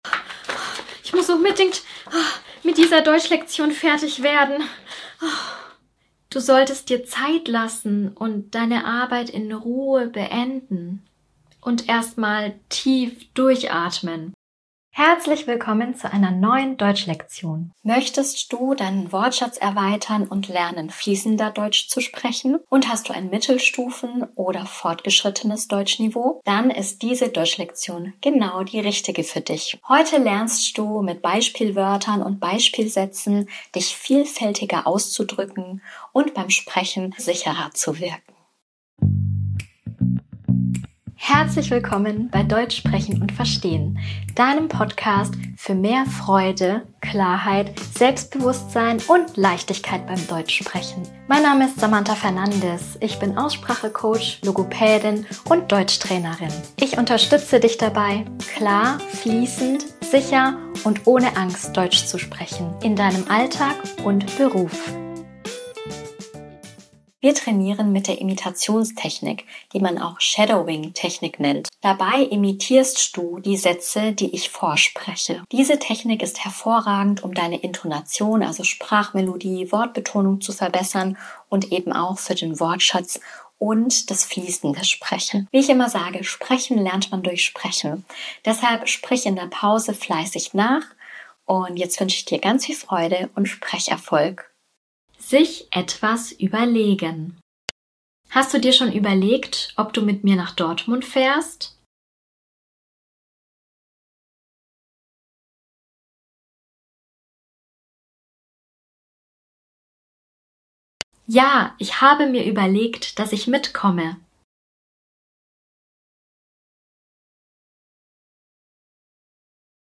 Du hörst kurze Mini-Dialoge und kannst